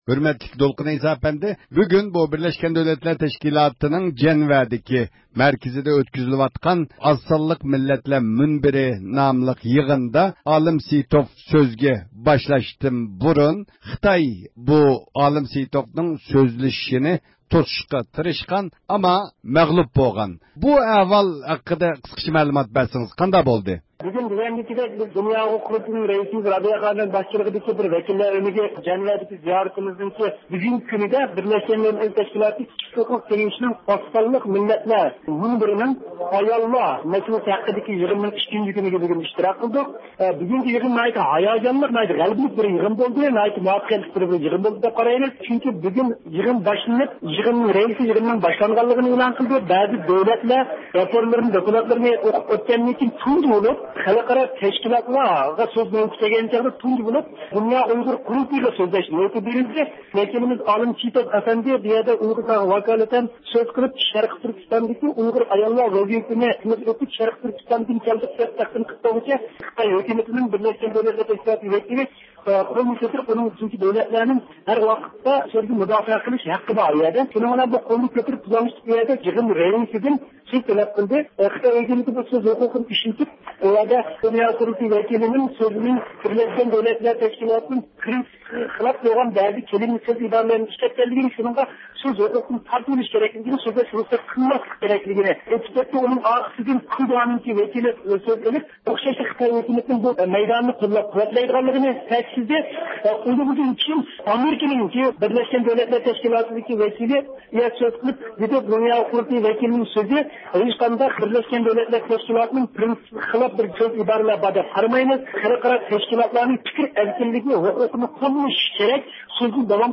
بىز بۇ ھەقتە يىغىن مەيدانىغا تېلېفون قىلىپ نەق مەيداندىن مەلۇمات ئىگىلىدۇق.